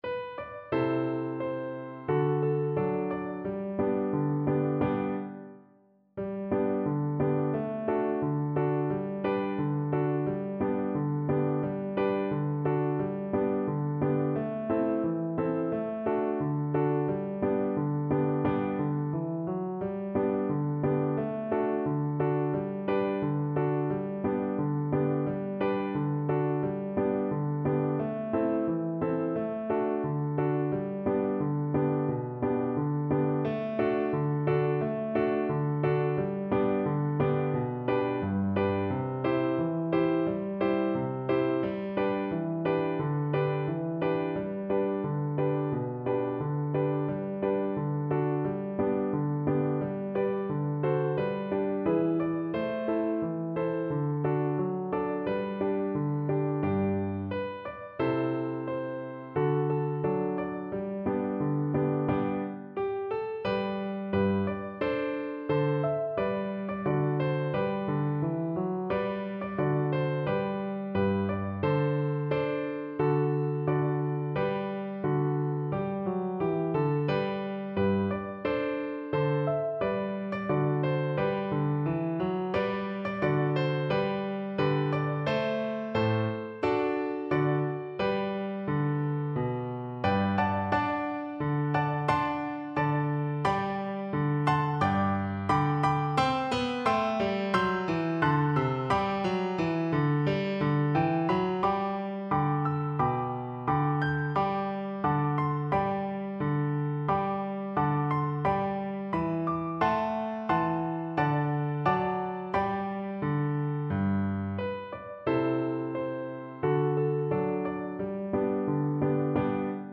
Flute
4/4 (View more 4/4 Music)
G major (Sounding Pitch) (View more G major Music for Flute )
Andante =c.88
Traditional (View more Traditional Flute Music)
Bavarian Music for Flute